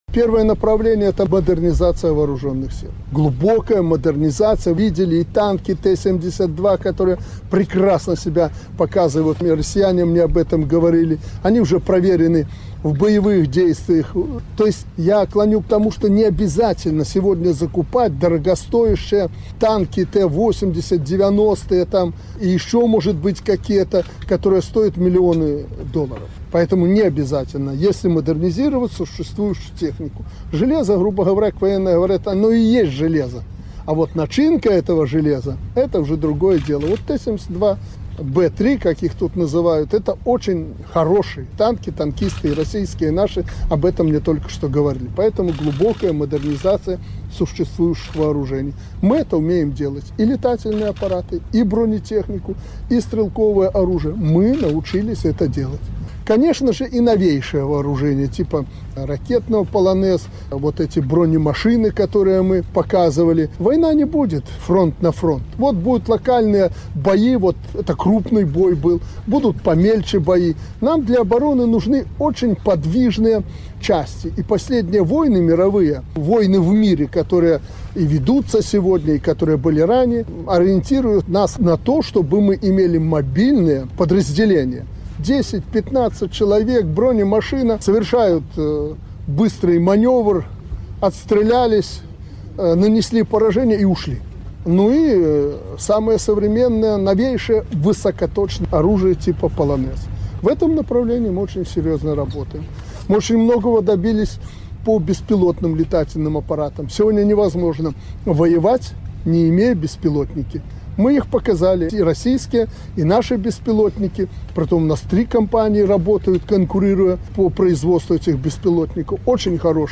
Военно-промышленный комплекс нашей страны продолжит глубокую модернизацию существующего вооружения и будет разрабатывать новые образцы. О направлении развития армии Президент Александр Лукашенко накануне рассказал журналистам.